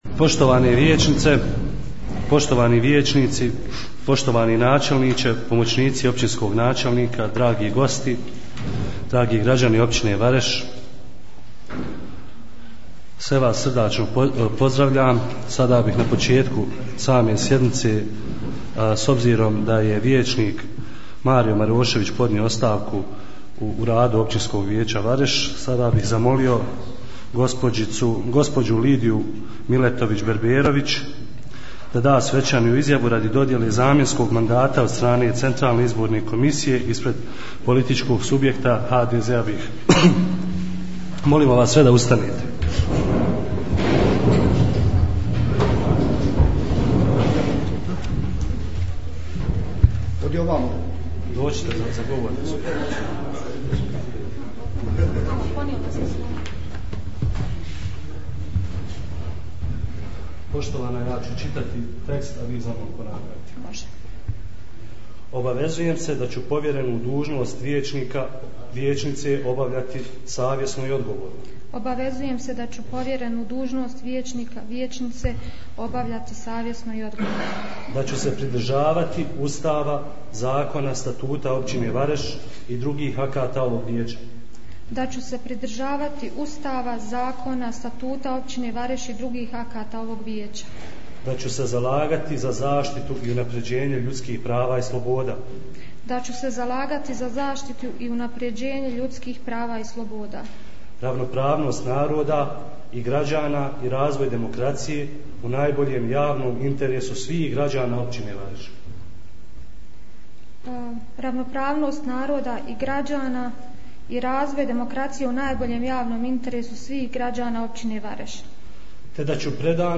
U povodu Dana neovisnosti Bosne i Hercegovine u Općini Vareš održana je 5. svečana sjednica Općinskog vijeća uz prigodan program. Nakon održavanja svečane sjednice održana je i 24. redovna sjednica Općinskog vijeća.